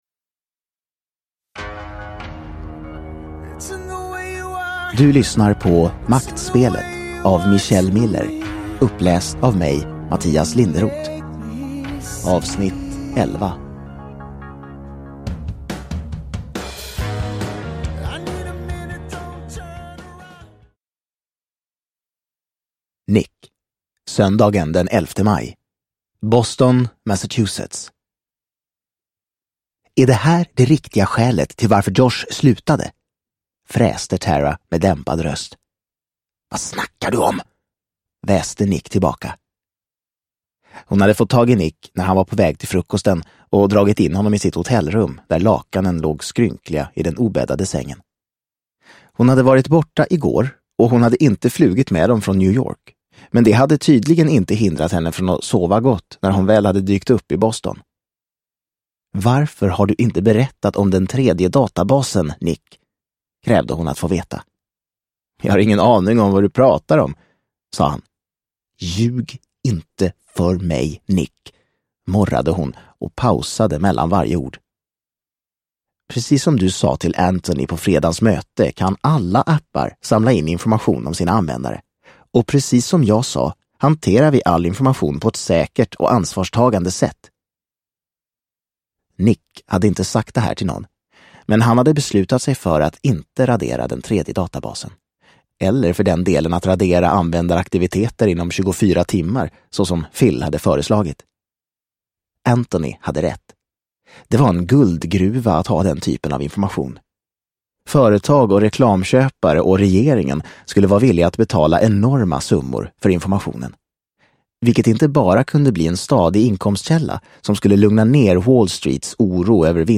Maktspelet Del 11 – Ljudbok – Laddas ner